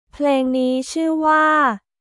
プレーン・ニー・チュー・ワー…